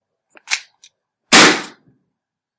Old school video games » submachinegun2
描述：took a bunch of hits and layered them. kick drum snare and hi hat
标签： game gun gunshot military video
声道立体声